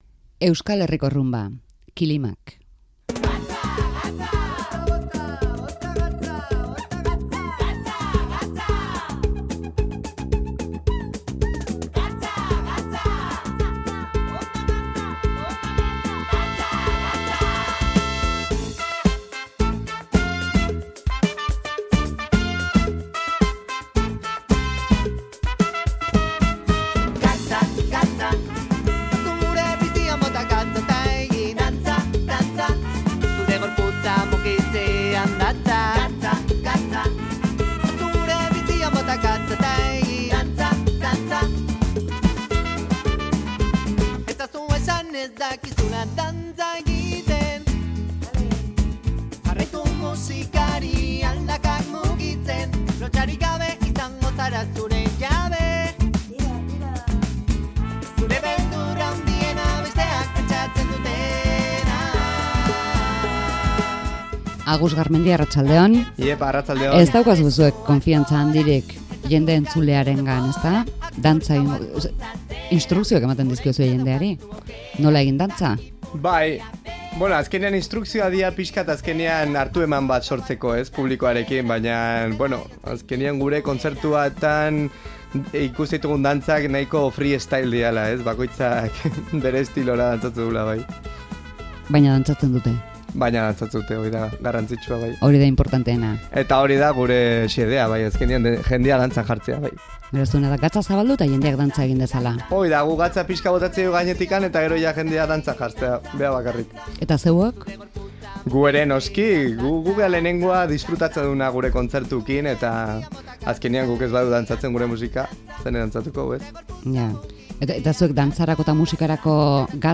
Runbak, kilimak eragiteko